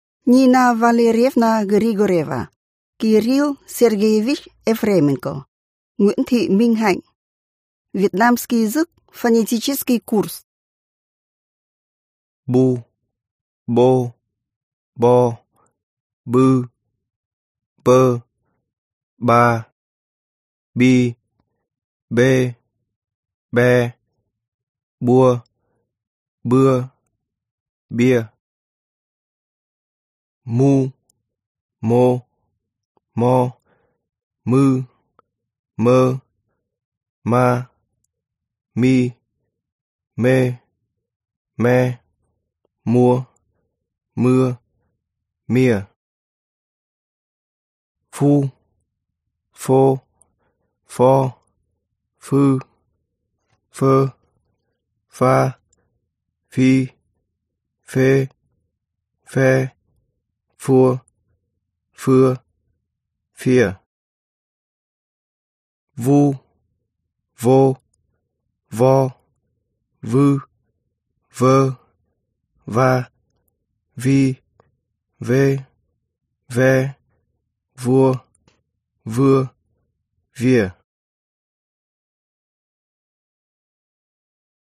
Аудиокнига Вьетнамский язык. Фонетический курс | Библиотека аудиокниг